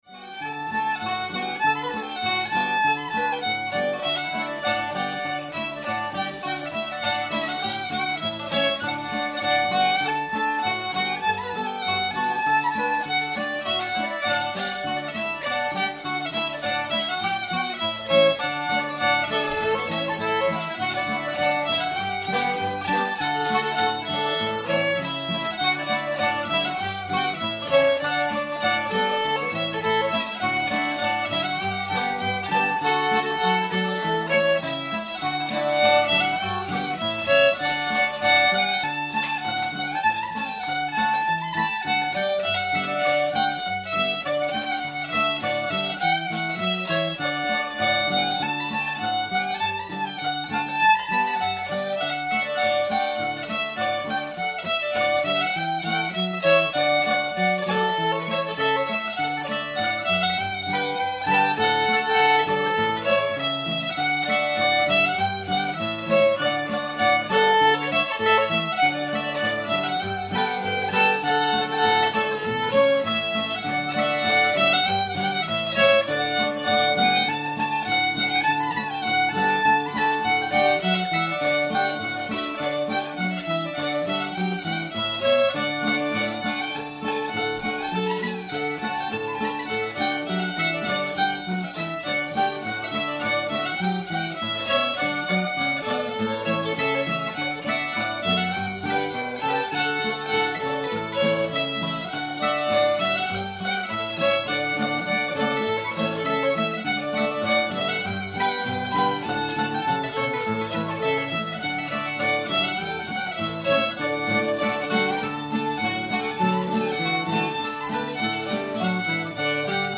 rock the cradle joe [D]